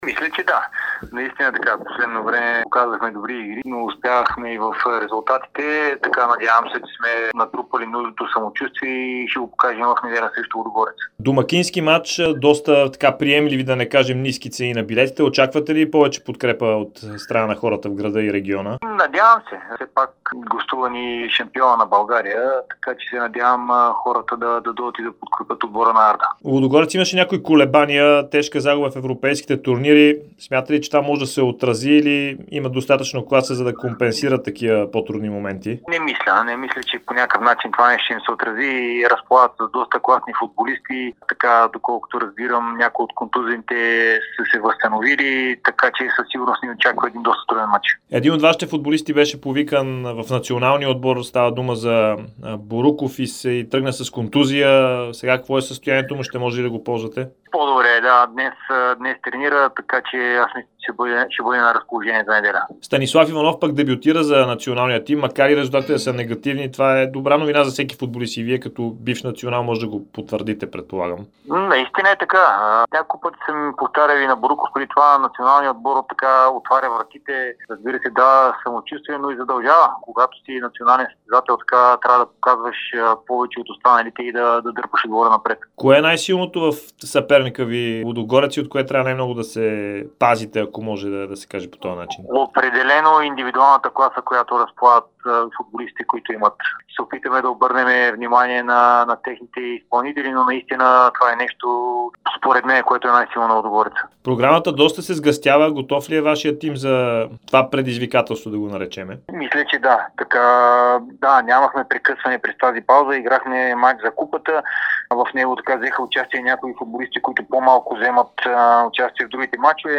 Старши треньорът на Арда Кърджали Александър Тунчев даде специално интервю пред Дарик радио и dsport преди домакинството на Лудогорец от 14-ия кръг на efbet Лига.